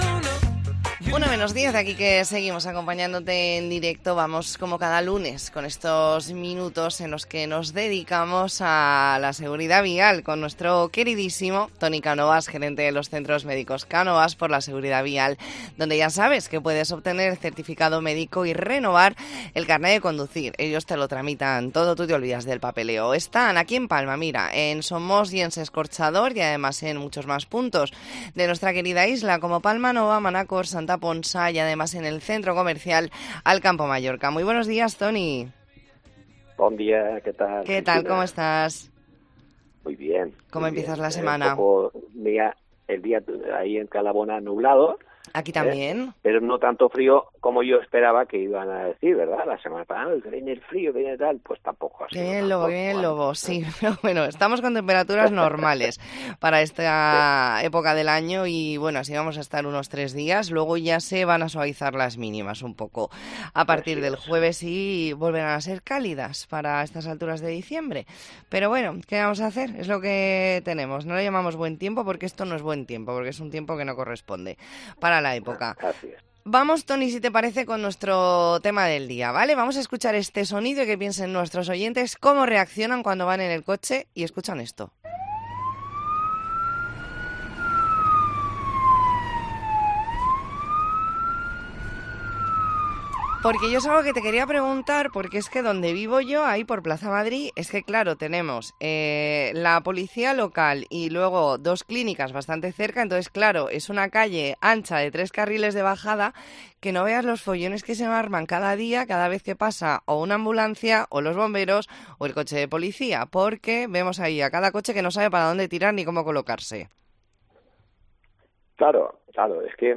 Entrevista en La Mañana en COPE Más Mallorca, lunes 4 de diciembre de 2023.